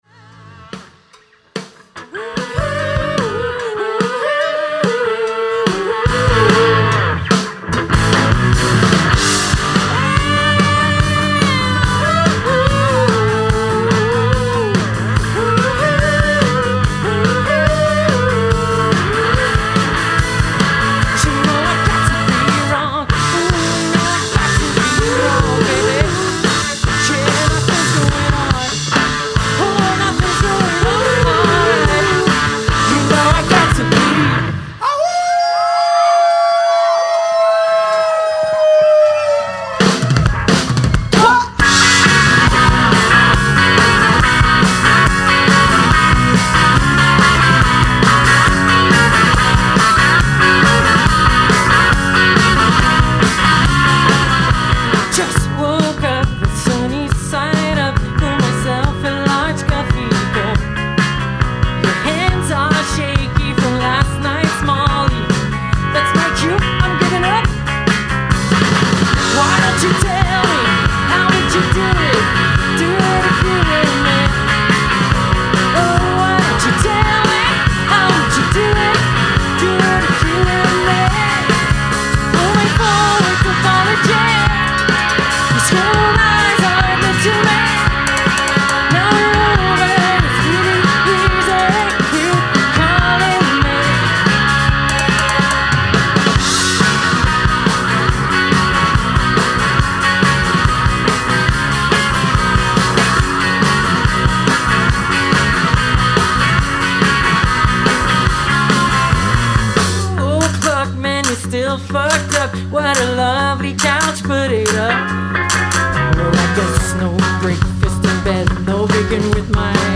Écoutez le spectacle complet ici !